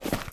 High Quality Footsteps
STEPS Dirt, Run 05.wav